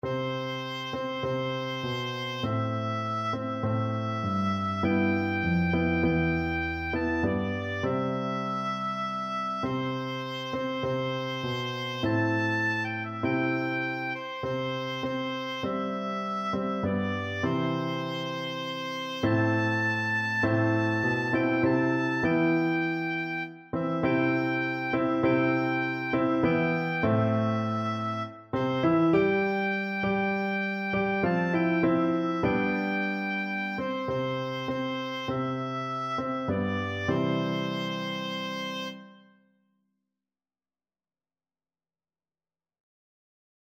Oboe
Steal Away is a spiritual from the African American tradition,
C major (Sounding Pitch) (View more C major Music for Oboe )
4/4 (View more 4/4 Music)
C6-A6